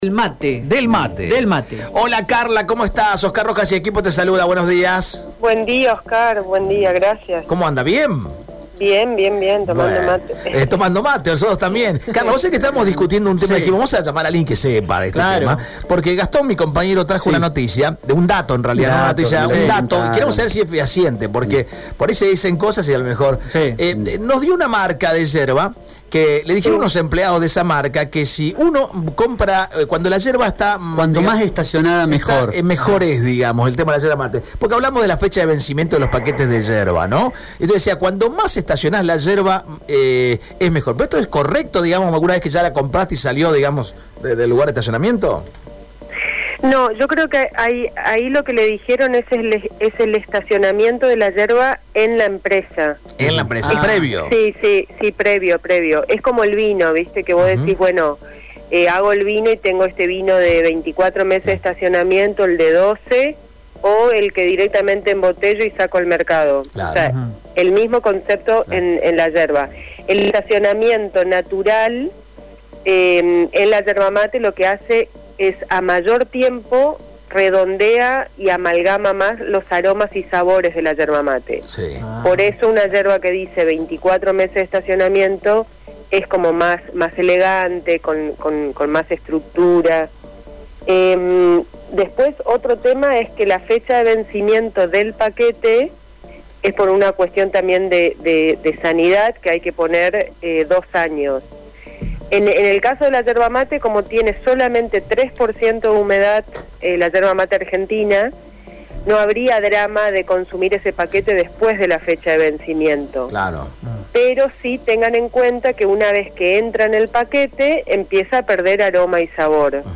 La especialista habló con Radio EME sobre los detalles que hay que tener en cuenta para que no falle la ceremonia argentina de tomar unos buenos mates.